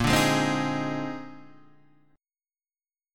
A# 11th